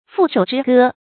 负手之歌 fù shǒu zhī gē
负手之歌发音